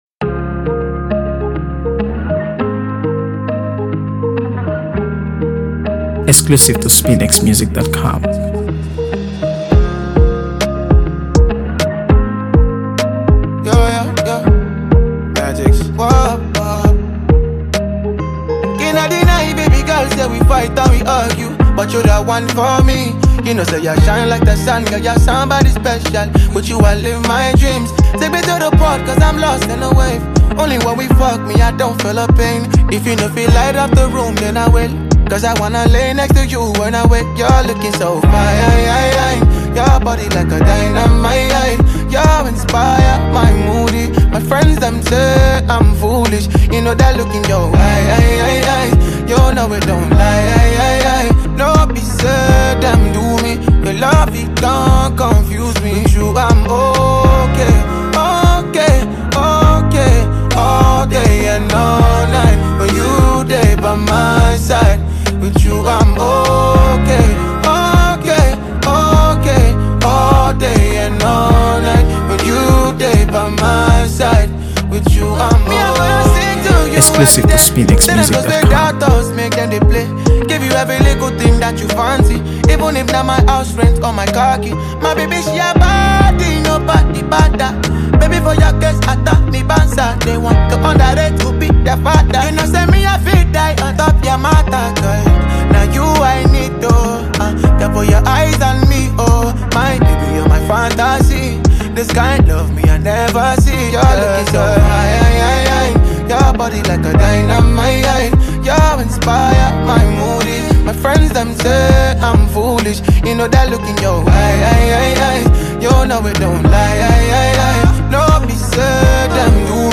AfroBeats | AfroBeats songs
Wrapped in lush Afrobeats production